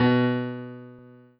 piano-ff-27.wav